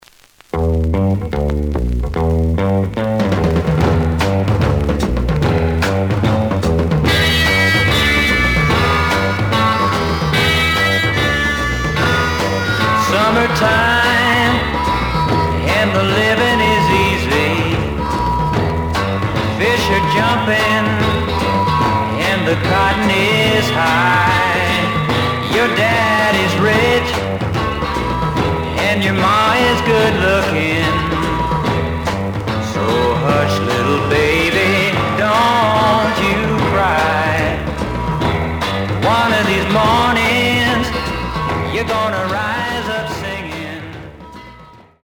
試聴は実際のレコードから録音しています。
●Genre: Rhythm And Blues / Rock 'n' Roll
●Record Grading: VG- (両面のラベルにダメージ。傷は多いが、プレイはまずまず。ジャケットなし。)